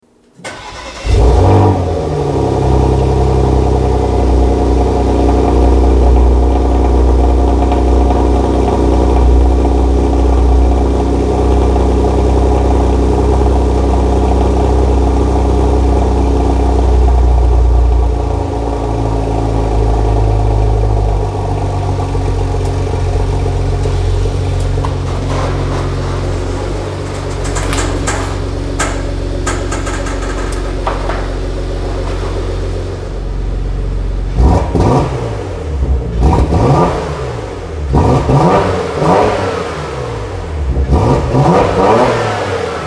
ENGINE SIZE 3.6 L twin turbo